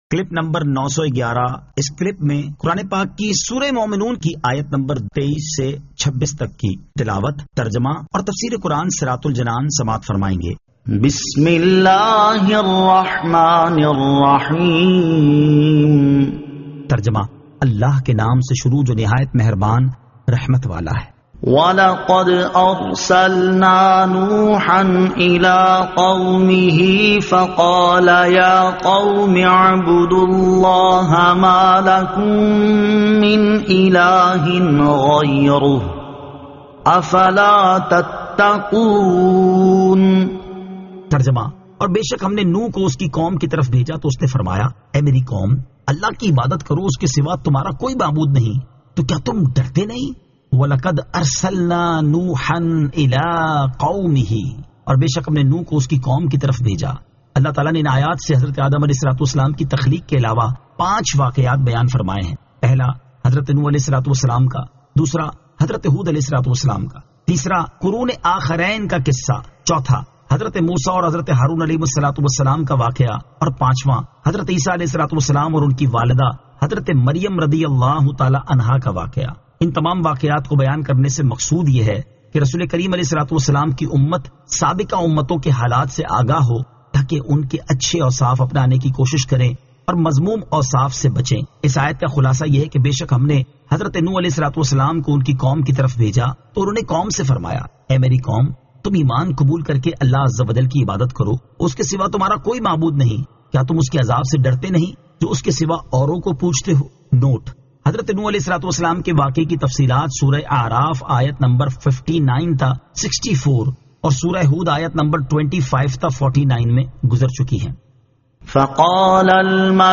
Surah Al-Mu'minun 23 To 26 Tilawat , Tarjama , Tafseer